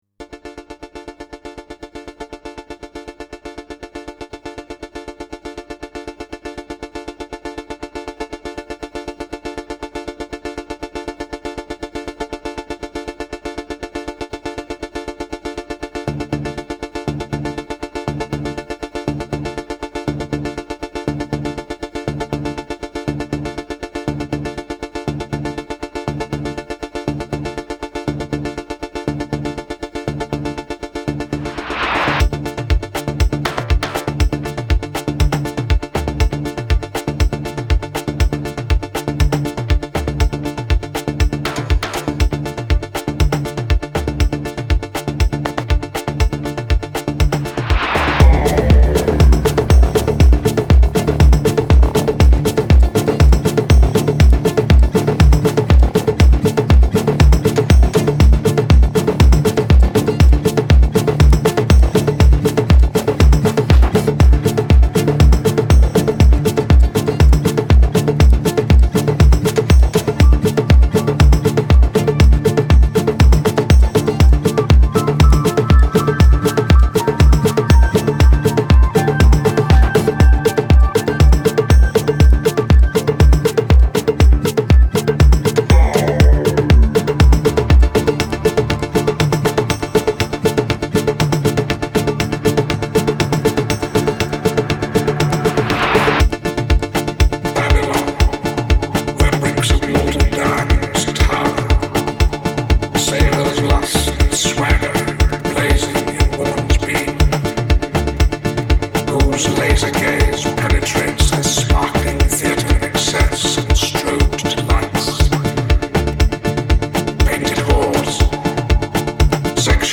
sinister disco jam